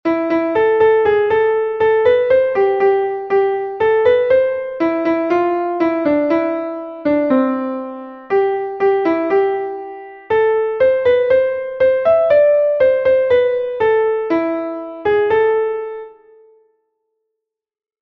Melodie: Volksweise aus Mähren